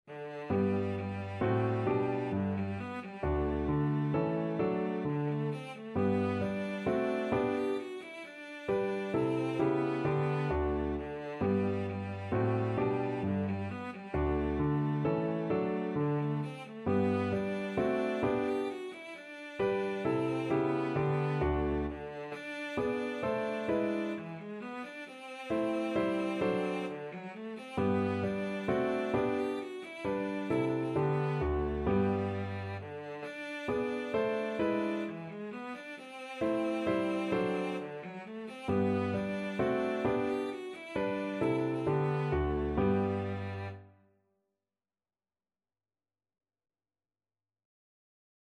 3/4 (View more 3/4 Music)
= 132 Allegro (View more music marked Allegro)
D4-G5
Classical (View more Classical Cello Music)